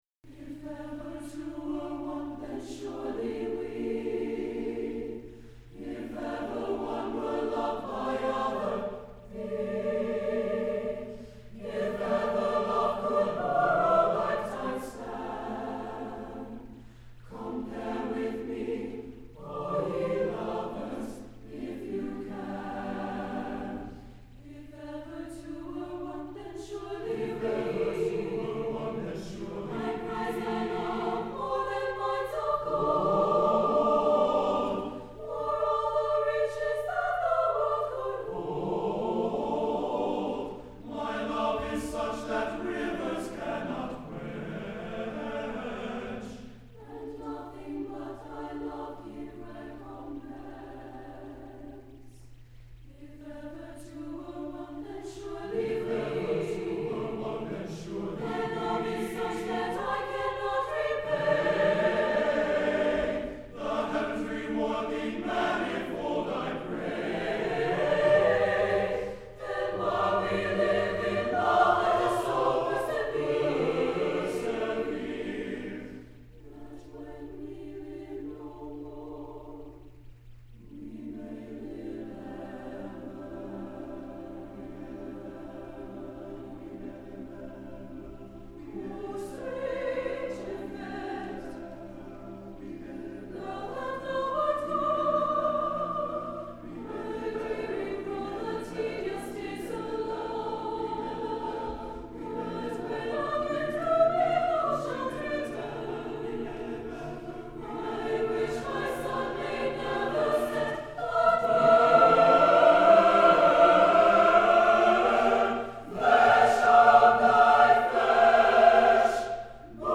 for SATB Chorus (2003)
The musical setting unfolds in three sections.